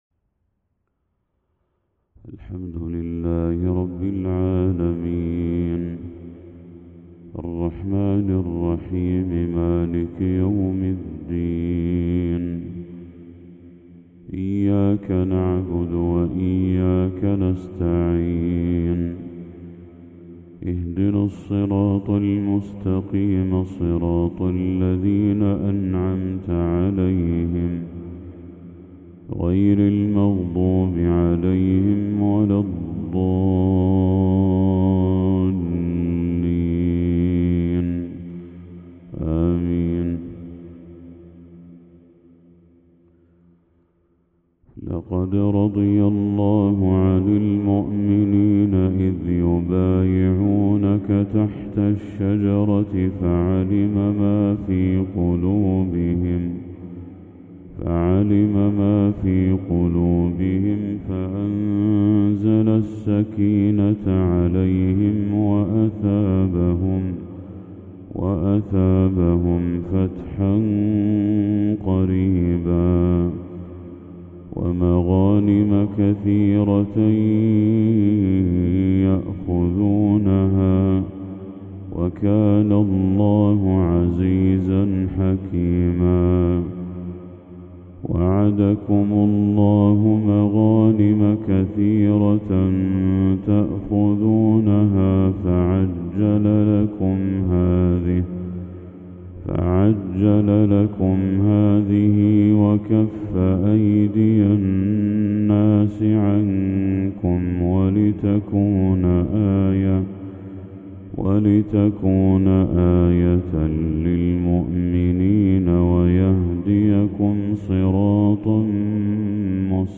تلاوة عذبة للشيخ بدر التركي خواتيم سورة الفتح | فجر 18 ذو الحجة 1445هـ > 1445هـ > تلاوات الشيخ بدر التركي > المزيد - تلاوات الحرمين